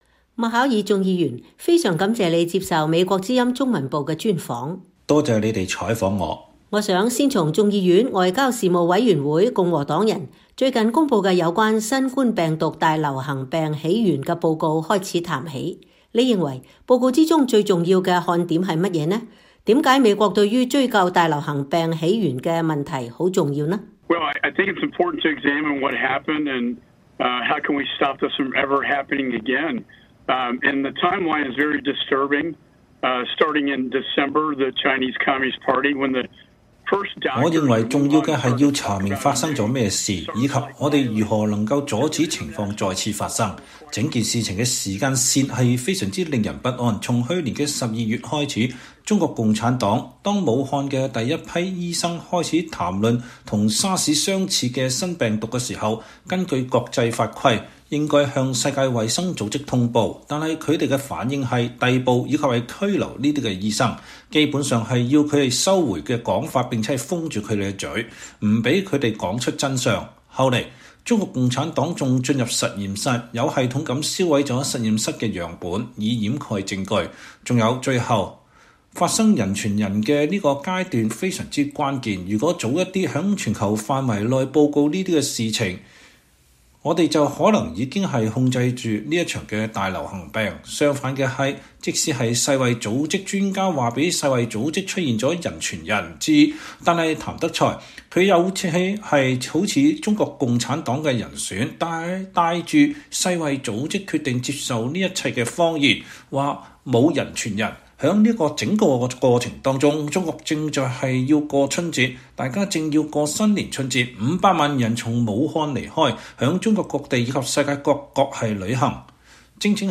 專訪眾議院外委會共和黨領袖：中共真正懼怕的是中國人民而非美國
眾議院外委會首席共和黨成員、來自德克薩斯州的眾議員麥考爾（Rep. Michael McCaul, R-TX）說，共產主義必將失敗，如同蘇聯和其他國家一樣。麥考爾7月7日從德克薩斯州接受美國之音視頻連線專訪時說，習近平領導下的中國共產黨對人民的壓迫和控制愈來愈嚴厲，中共最害怕的其實不是美國，而是本國人民。